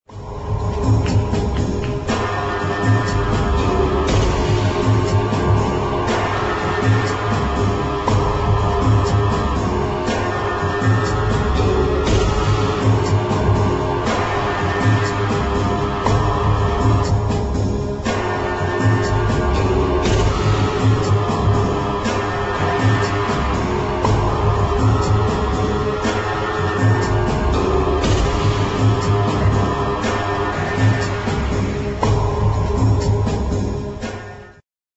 Elektrotwist
thrilling slow instr.